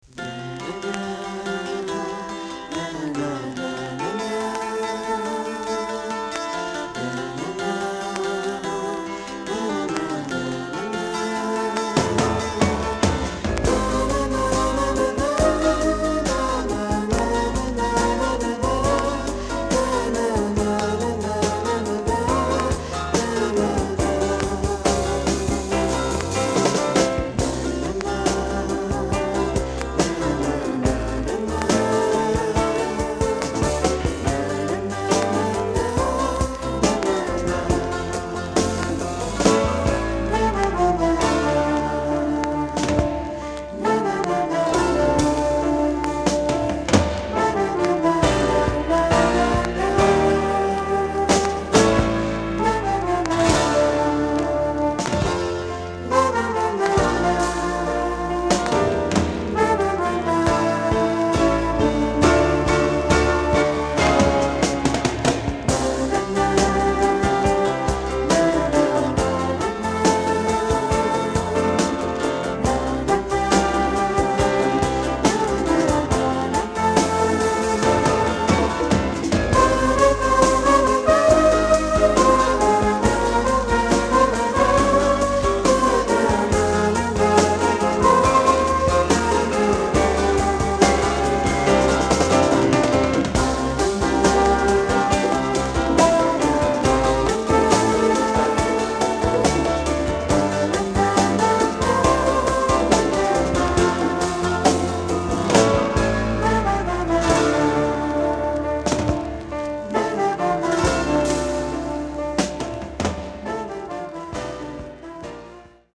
disk : VG+ to EX- （A-5前半でスリキズによるプチ音が出ます）